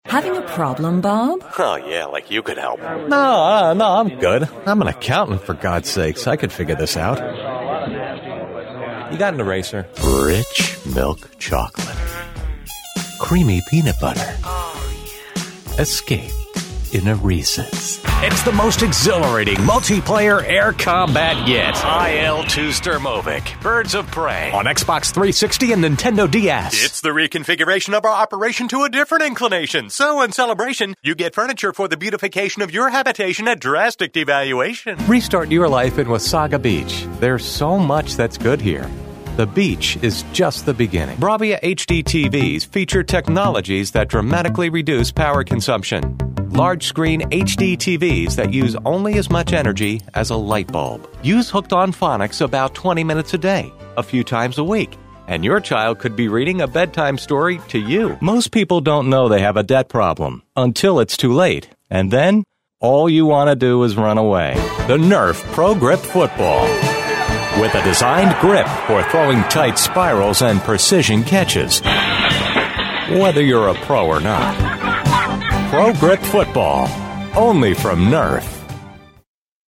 Energetic-Professional-Articulate-Sincere-Authoritative-Friendly-Familiar-Trustworthy-Inviting- Smooth-Crisp-Dynamic-Informative-Clear-Educational-Corporate-Intelligent.
englisch (us)
mid-atlantic
Sprechprobe: Werbung (Muttersprache):